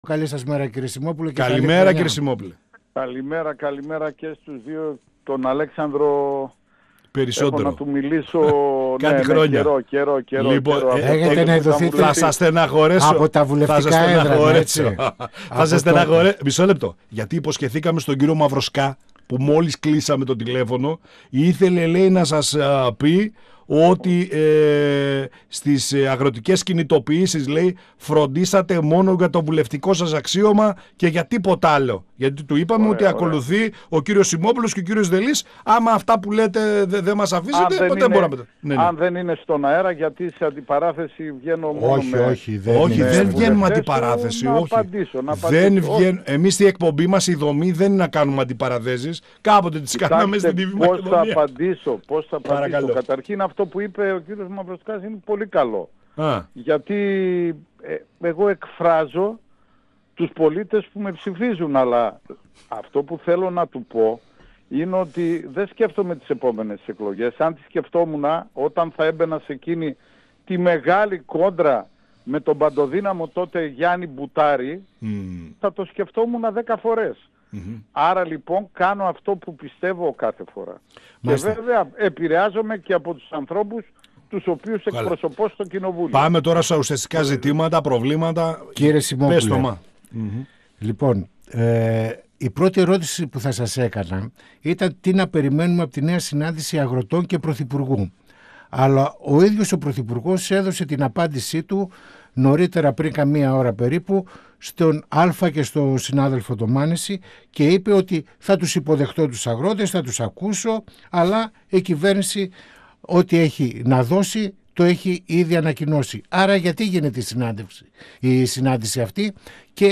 Στα αγροτικά μπλόκα, στο σκάνδαλο του ΟΠΕΚΕΠΕ, αλλά και το πρόβλημα της ακρίβειας σε συνδυασμό με την αγοραστική δύναμη των πολιτών αναφέρθηκε ο Βουλευτής της ΝΔ Στράτος Σιμόπουλος, μιλώντας στην εκπομπή «Πανόραμα Επικαιρότητας» του 102FM της ΕΡΤ3.
Συνεντεύξεις